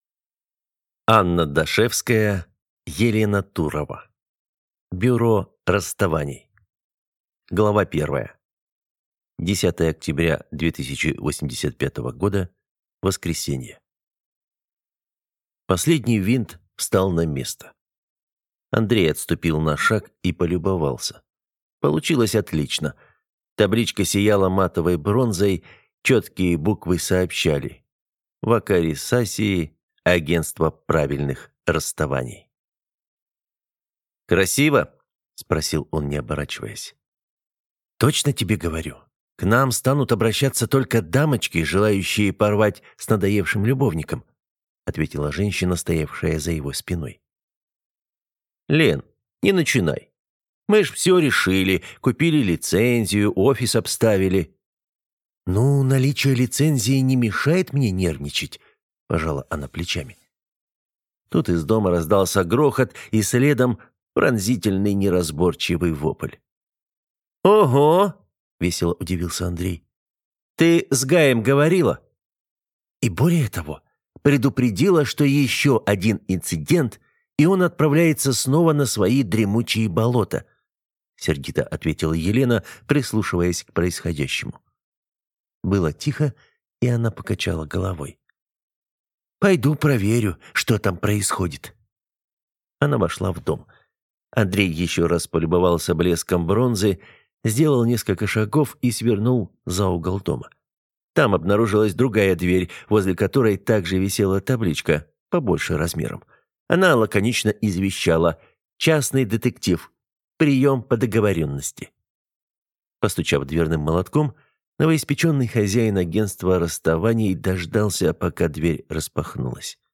Аудиокнига Бюро расставаний | Библиотека аудиокниг